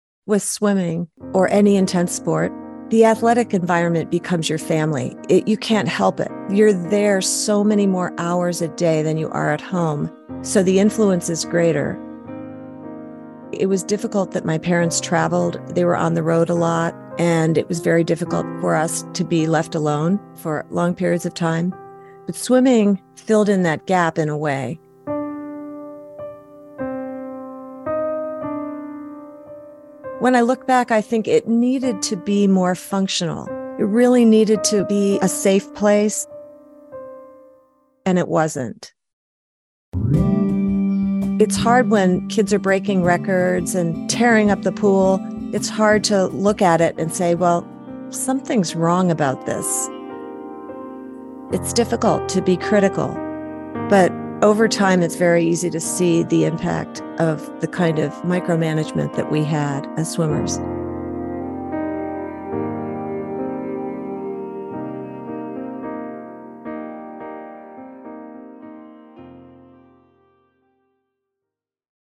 Interviews and audio